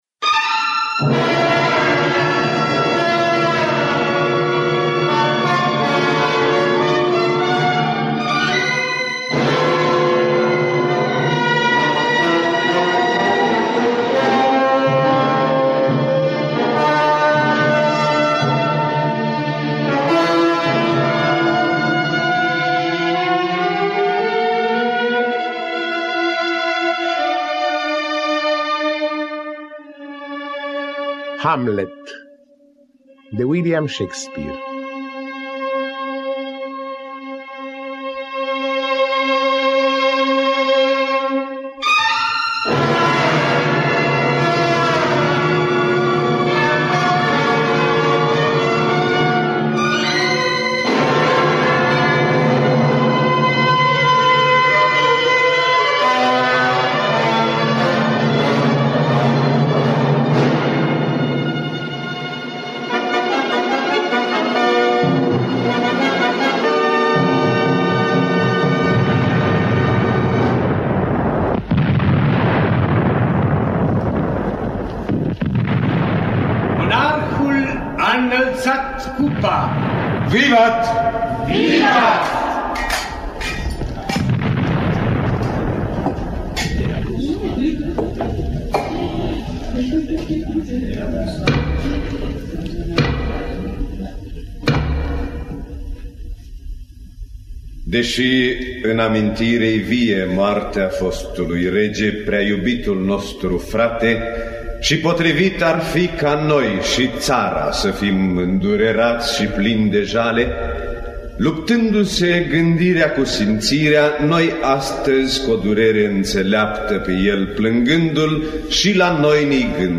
Adaptare radiofonică